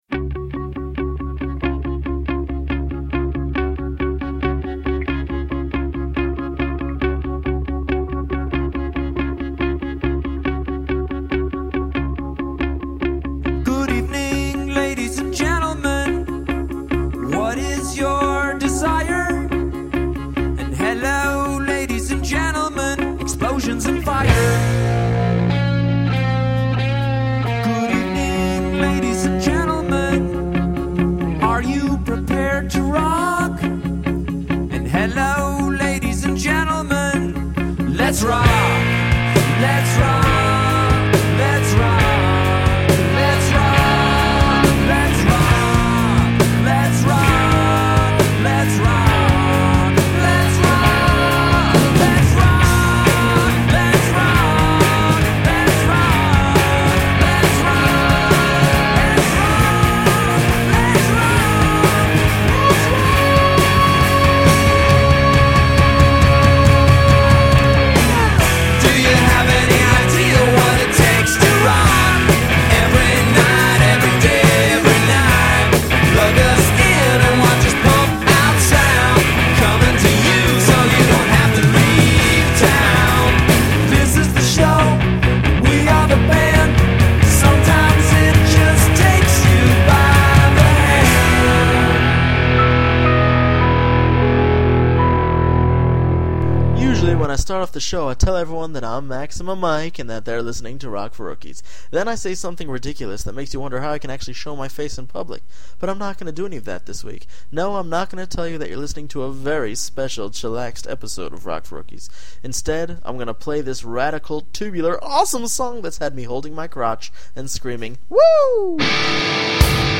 Just a chill show!!!